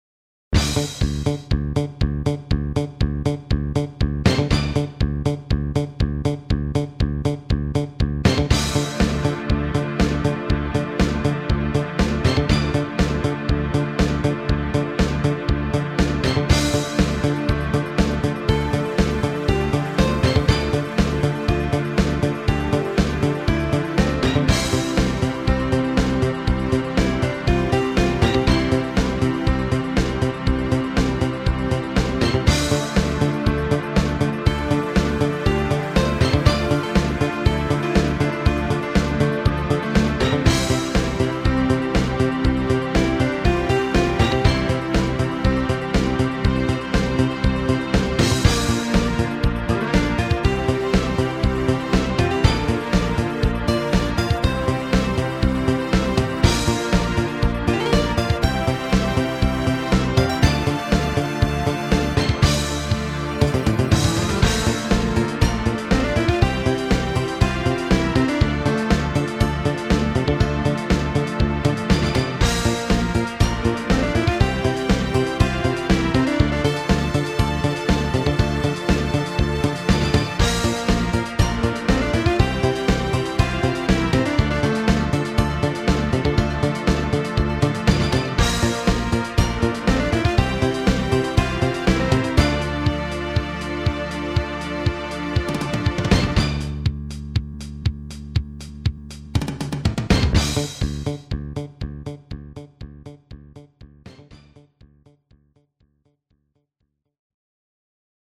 某サンプラー音源直録りです。
メモリ不足でほとんどの音色のサンプリング周波数が9KHz（一部11KHz）となってしまい、
苦労に苦労を重ねて少ない容量でクリアな音を出すことに全力を挙げました。